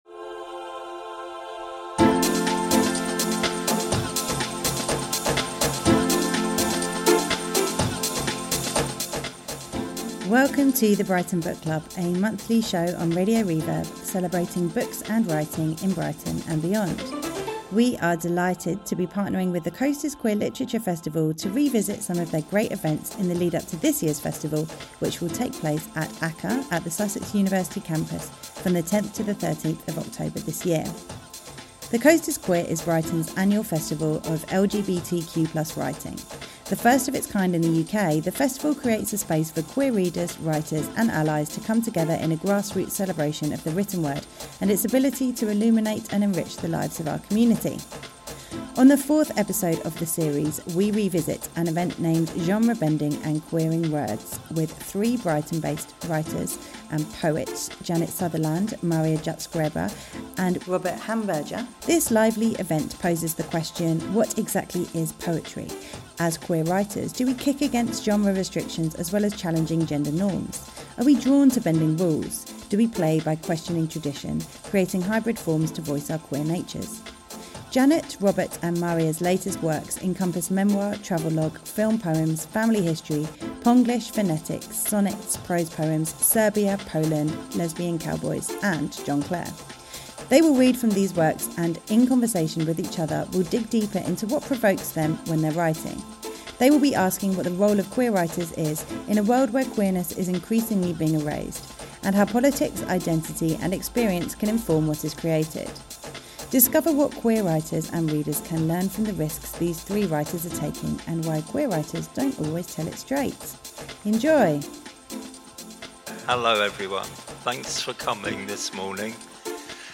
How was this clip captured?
In the fourth of our five-part series in collaboration with The Coast is Queer Festival , we revisit the event Genre Bending and Queering Words from 2023's festival with three Brighton based authors and poets